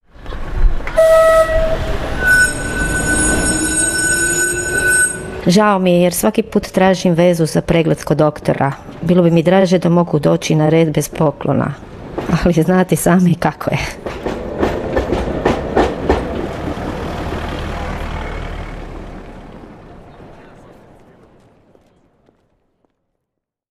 radio intervention
STATEMENTS BROADCASTED ON RADIO "SLJEME":